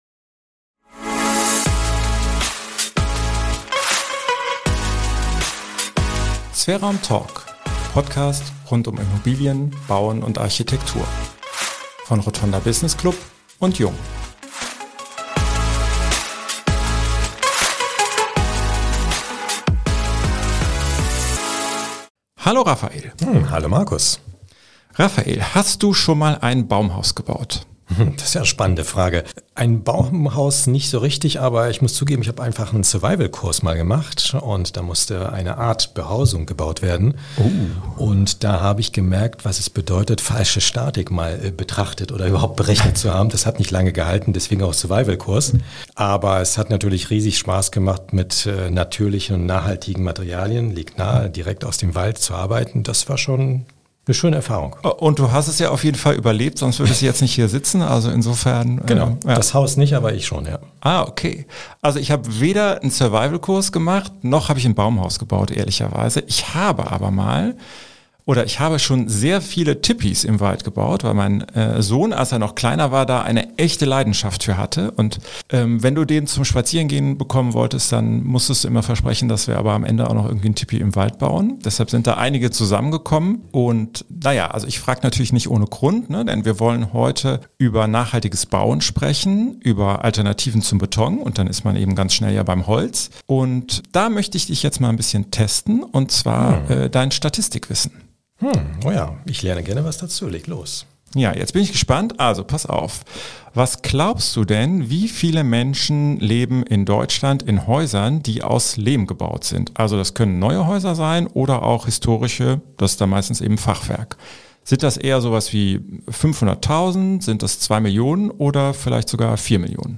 Ein Gespräch über einen jahrtausende alten und zugleich innovativen Baustoff, seine überraschenden Eigenschaften und die Frage: Wie überwindet man die Hürden, damit er in Deutschland und Europa endlich mehr verbaut wird?